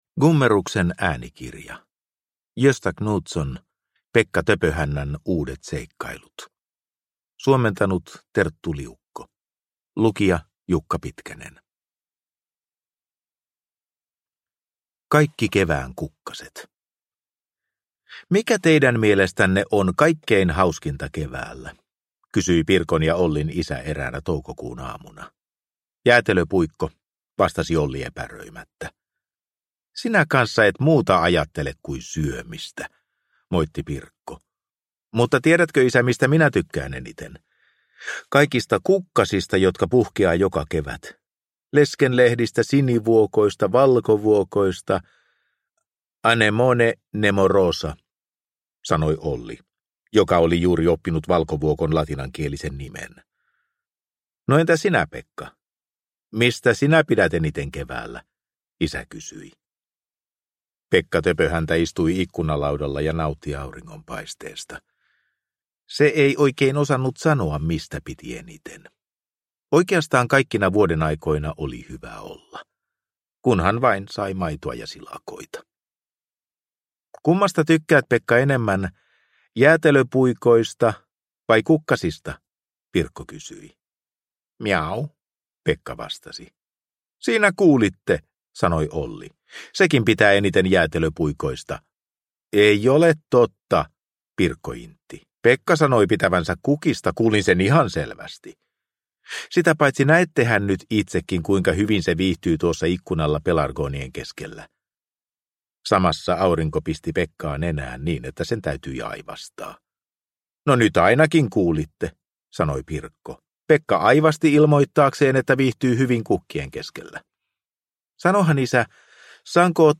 Pekka Töpöhännän uudet seikkailut – Ljudbok – Laddas ner